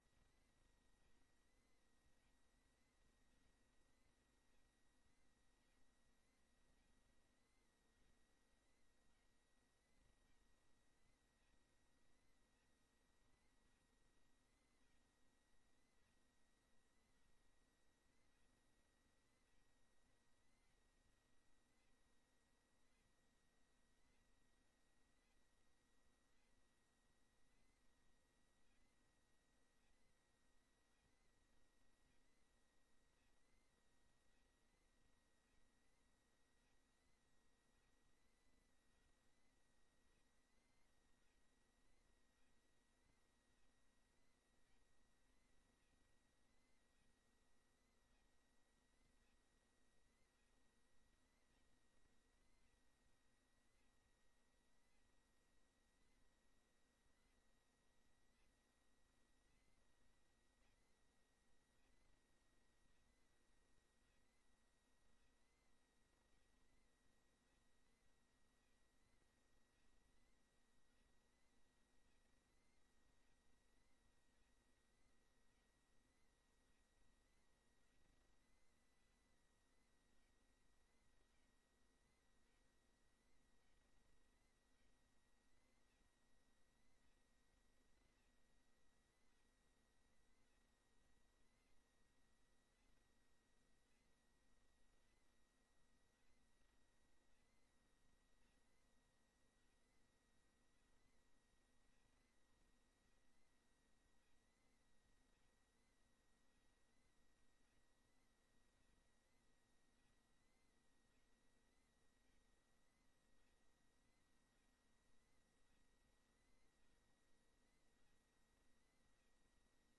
Raadscommissie 12 november 2024 20:00:00, Gemeente Renkum
Download de volledige audio van deze vergadering
Locatie: Raadzaal